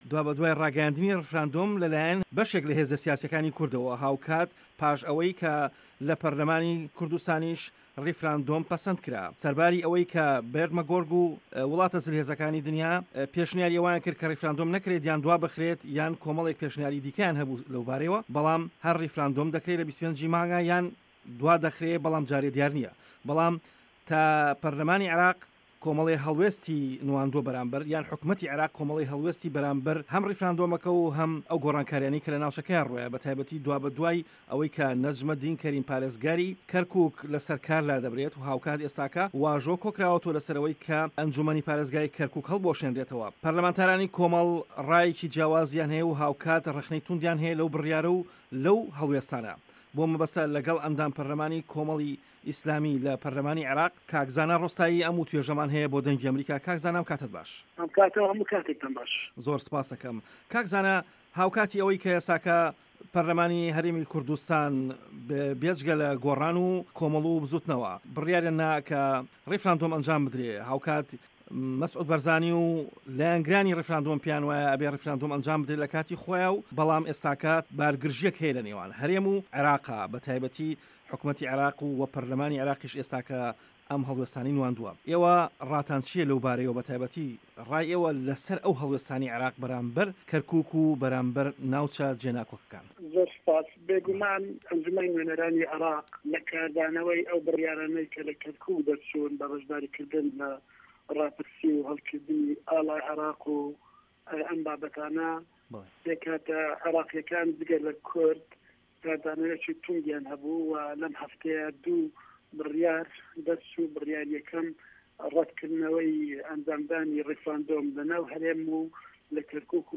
وتووێژی زانا ڕۆستایی